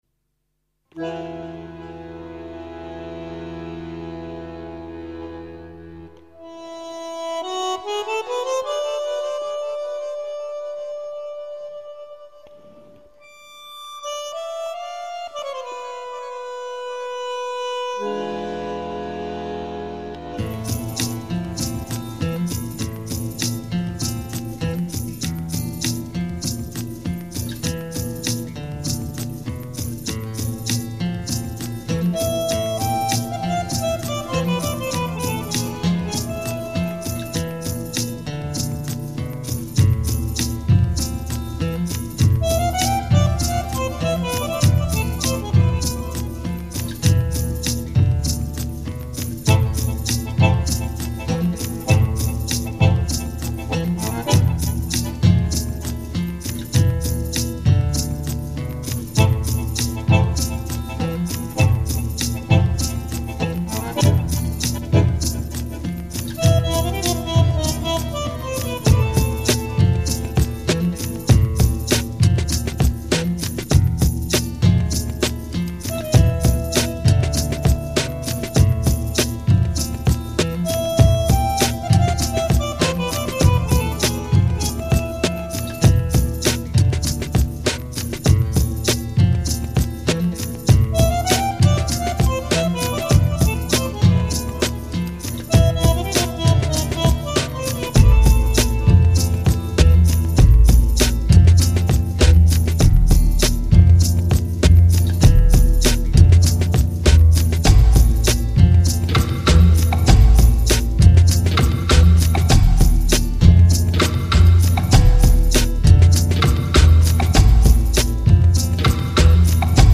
涉及分类：电子音乐 Electronica